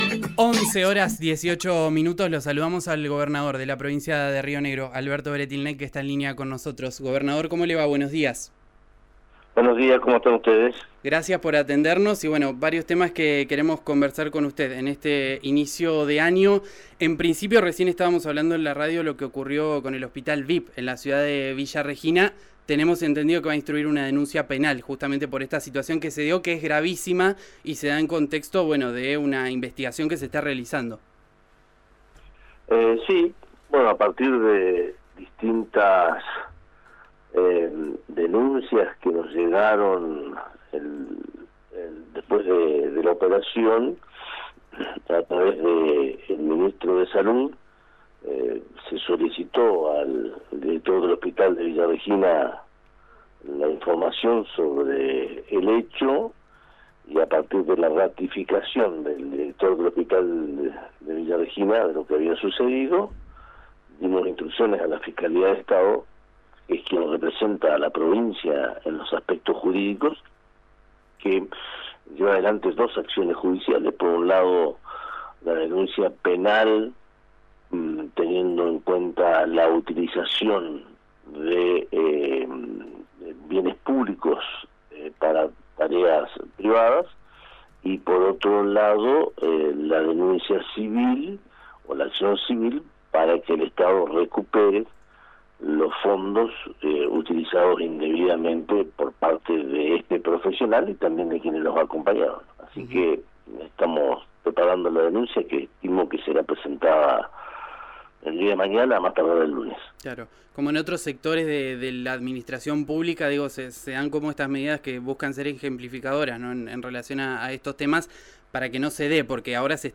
Escuchá al gobernador Alberto Weretilneck en RÍO NEGRO RADIO:
El gobernador Alberto Weretilneck confirmó en una entrevista con RÍO NEGRO RADIO, que el secretario de Asuntos Estratégicos, Milton Dumrauf se hará cargo del ministerio de Modernización a partir de la salida de Federico Lutz, que se formalizará a fin de mes.